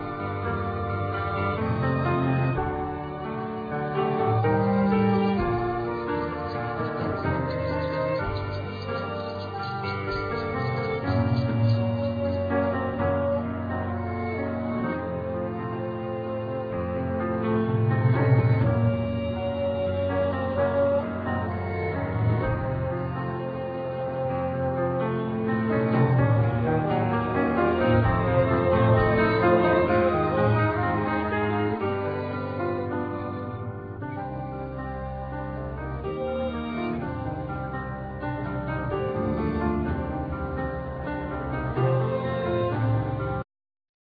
Violin
Basson
Piano
Doublebass
Drums,Percussions
Vibes,Marimba,Percussions
Vocals
Trumpet,Flugelhorn
Trombone
Bass Clarinat